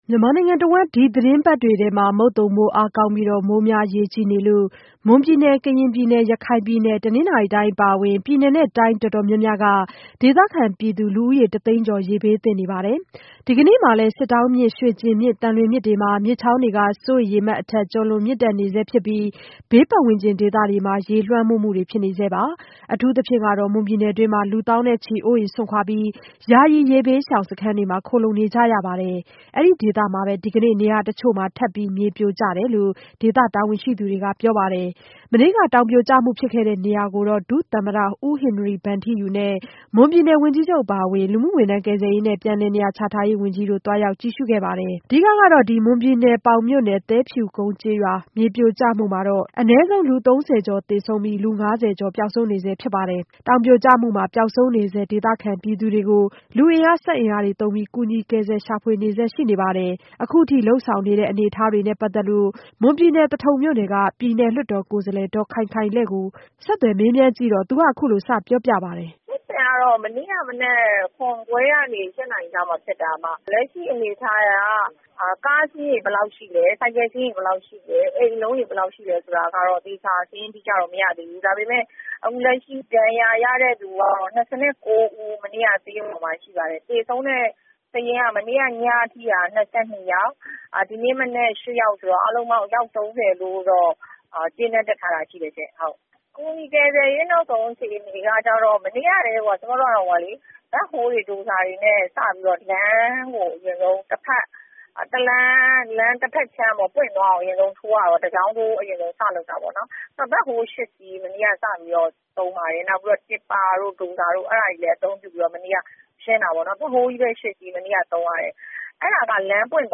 မွန်ပြည်နယ် သဘာဝဘေးအခြေအနေ ပြည်နယ်အမတ် မေးမြန်းချက်